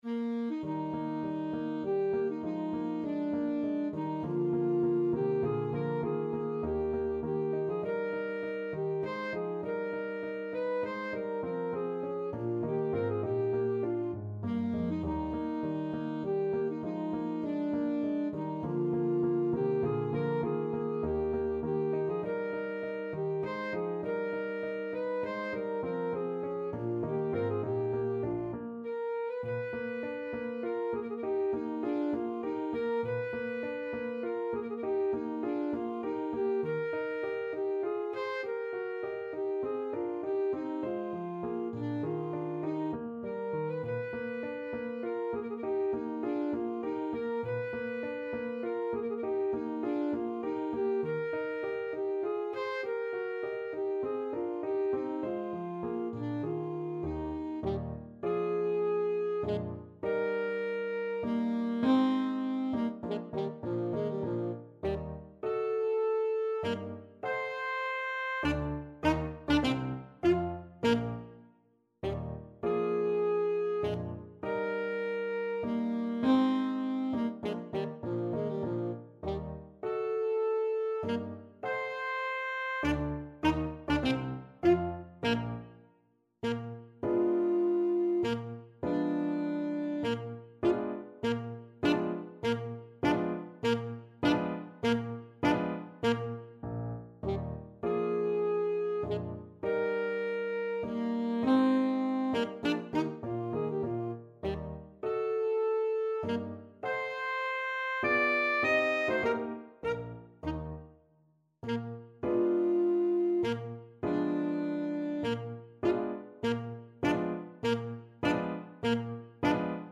Alto Saxophone
3/4 (View more 3/4 Music)
Menuetto Moderato e grazioso
Classical (View more Classical Saxophone Music)